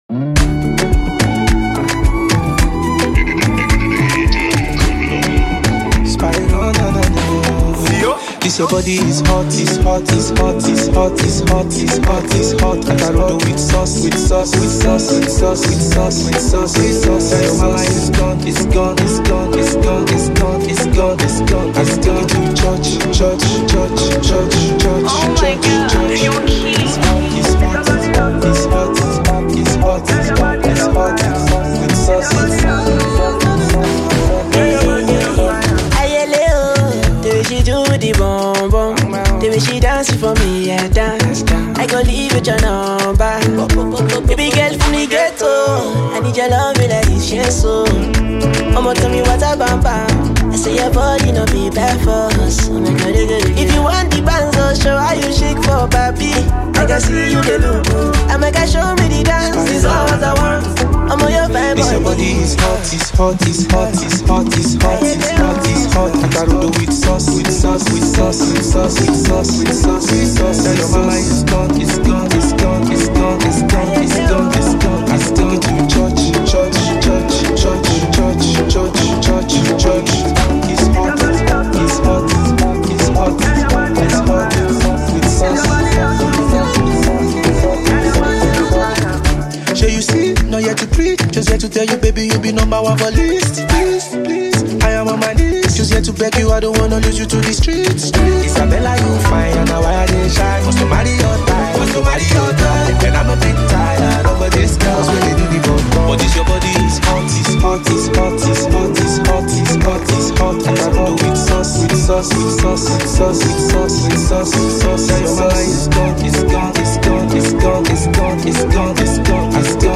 guitarist
infectious Afrobeats rhythms
smooth, feel-good vocals
a confident, melodic touch that elevates the overall sound